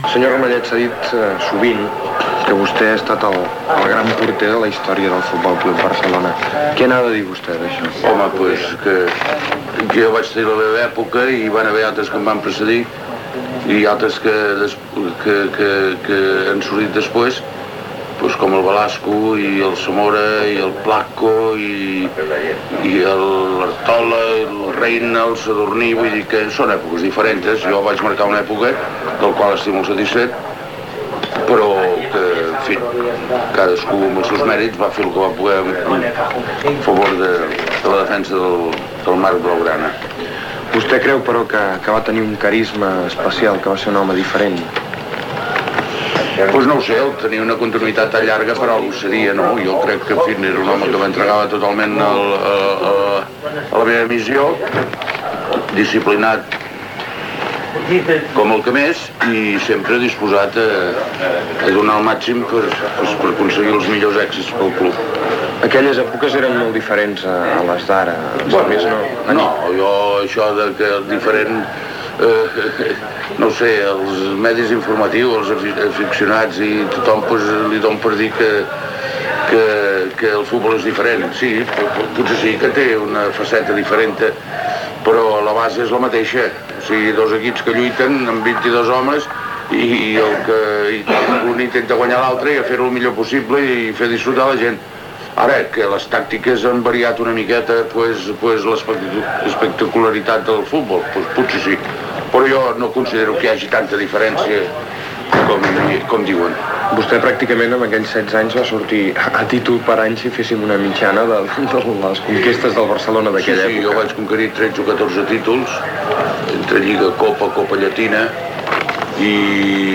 Entrevista al porter del F.C: Barcelona Antoni Ramallets sobre la seva trajectòria esportiva
Esportiu